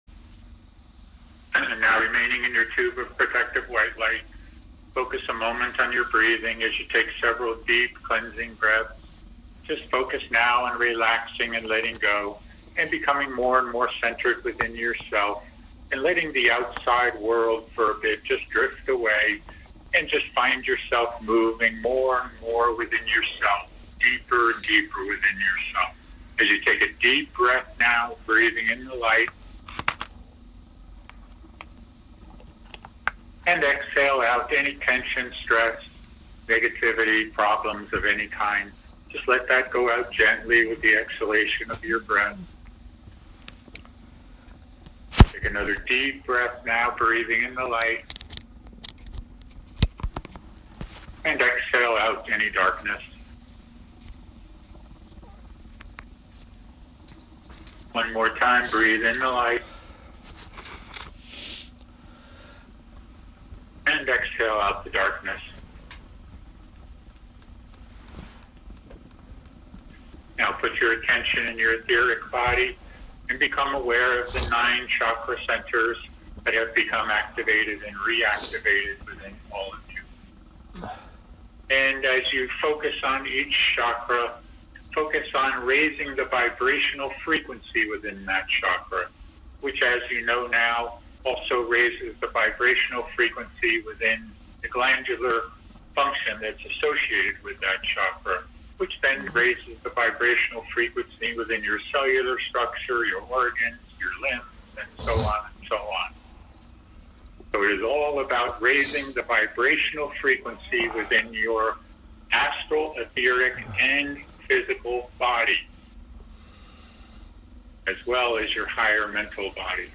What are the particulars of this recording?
Audio Recording Meditation – Minute (00:00) Follow along in group meditation with Archangel Michael Channeling – Minute (19:57) I am Archangel Michael, and I come to be with you at this time in these moments, in these changing timelines that you are in.